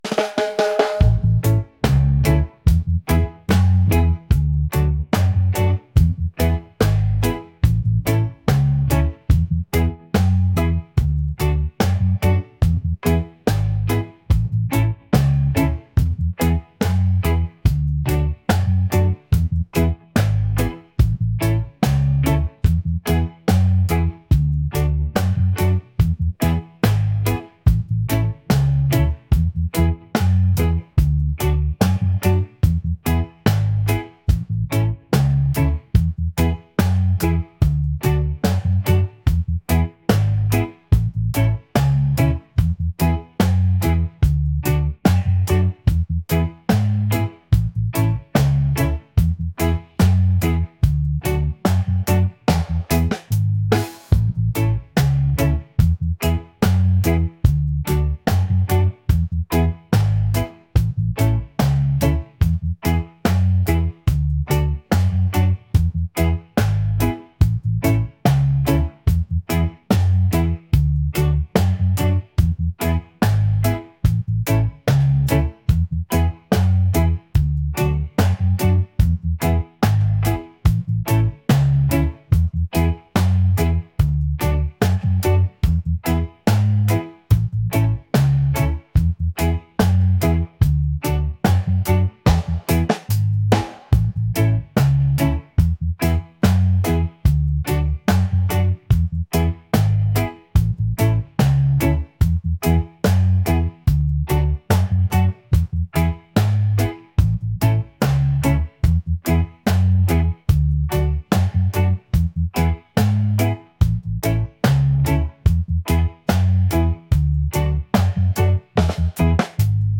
lively | upbeat | reggae